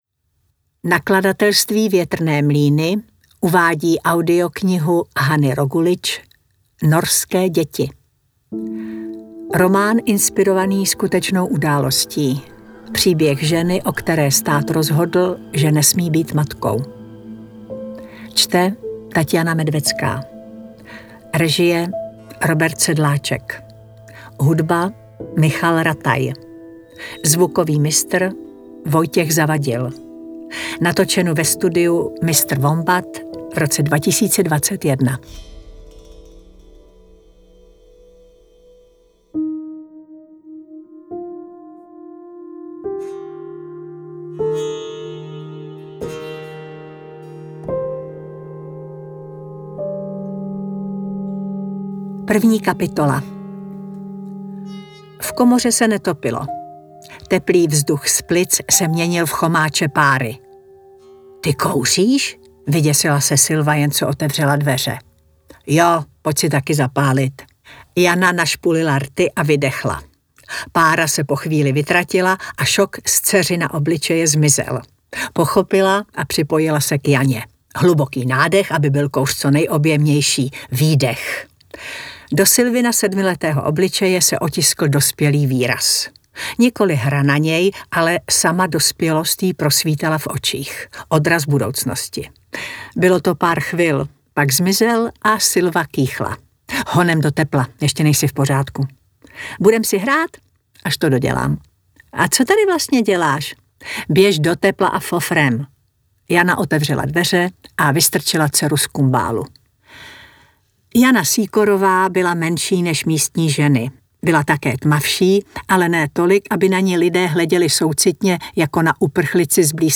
Interpret:  Taťjána Medvecká
AudioKniha ke stažení, 1 x mp3, délka 10 hod. 48 min., velikost 1016,7 MB, česky